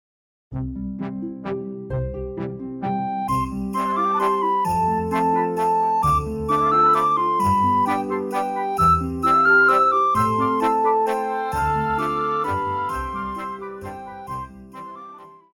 高音直笛
樂團
聖誕歌曲,傳統歌曲／民謠,聖歌,教會音樂,古典音樂
鋼琴曲,演奏曲
獨奏與伴奏
有主奏
有節拍器